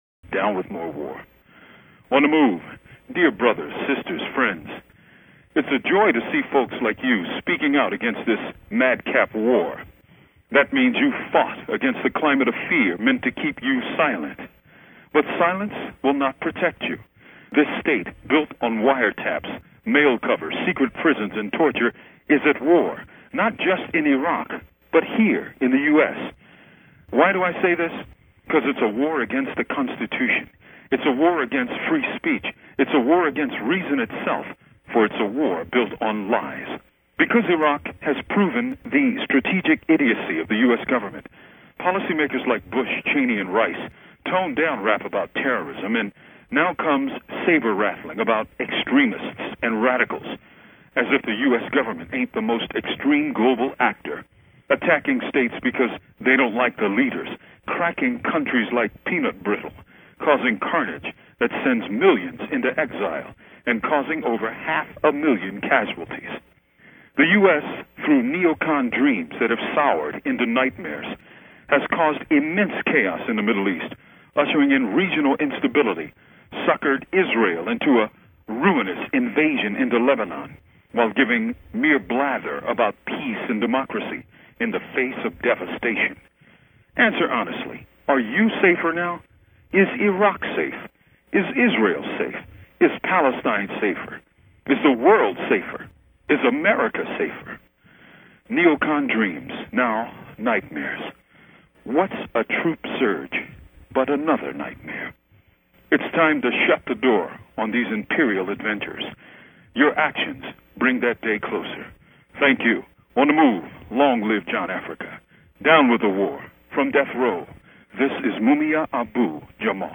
Mumia Abu-Jamal's Speech to Anti-War Demo & more